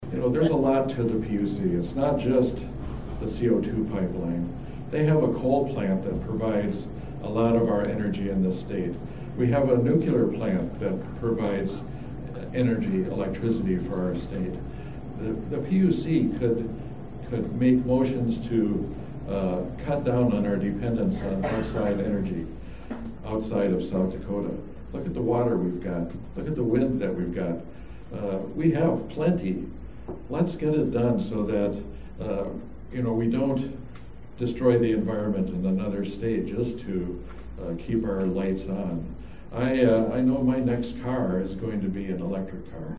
At their 2022 State Convention over the weekend (July 8-9, 2022) in Fort Pierre, the South Dakota Democratic Party nominated candidates for constitutional offices, adopted its platform, adopted an amendment to the constitution and passed resolutions.